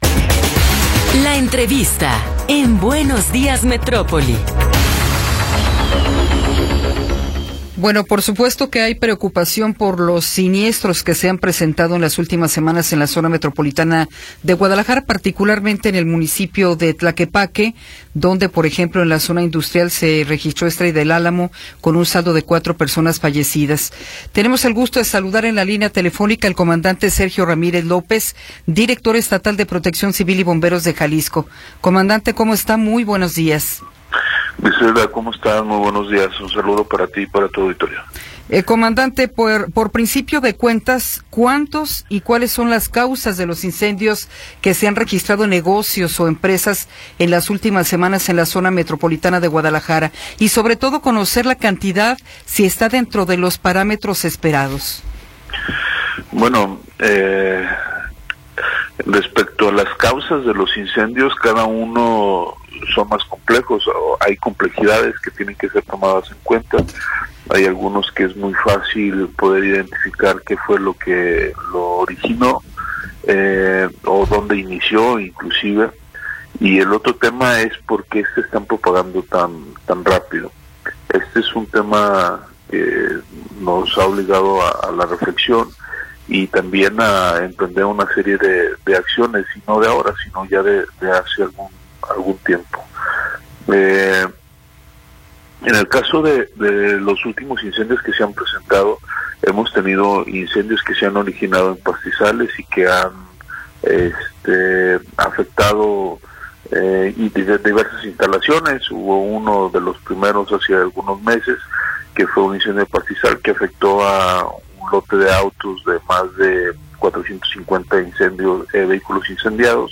Entrevista con Sergio Ramírez López